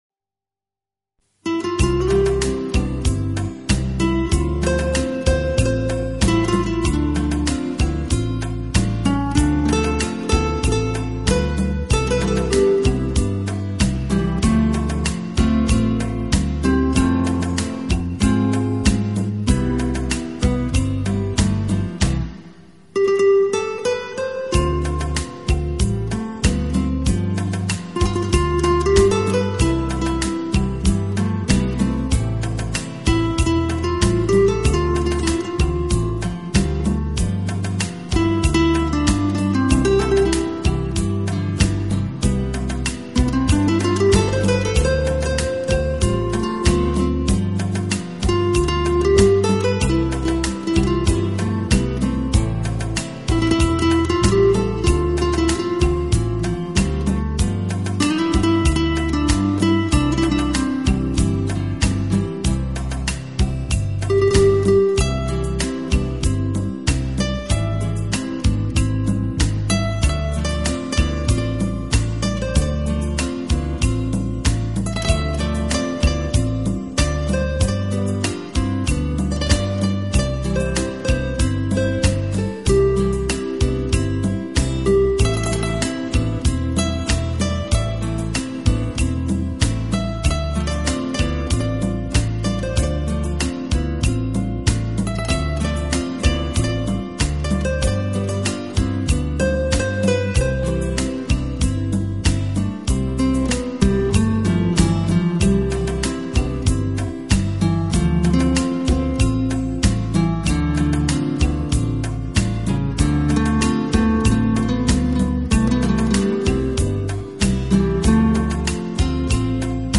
专辑音色清脆动人且温馨旖丽，不禁展示了精彩绝伦的空间感，而且带出吉他音箱共鸣
浪漫吉他曲，经典西洋乐，音符似跳动的精灵，释放沉睡已久的浪漫情怀，用吉他的清